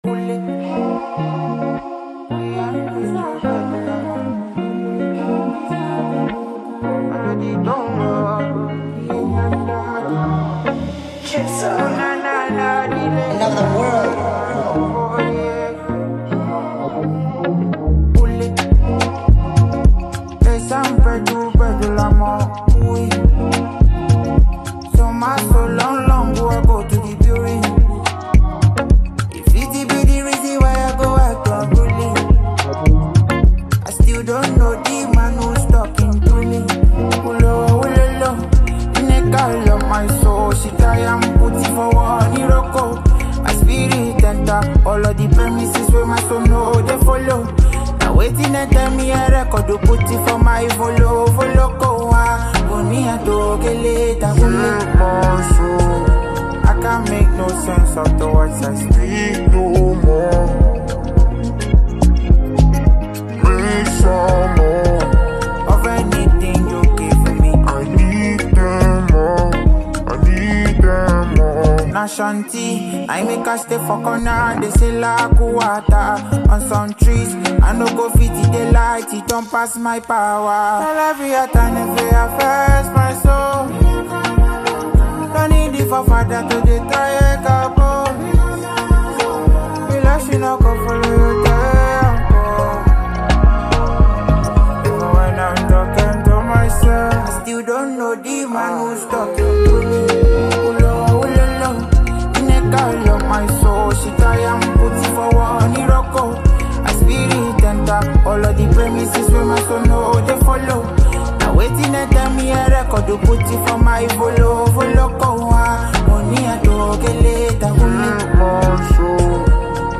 Nigeria talented Afrobeats music singer and songwriter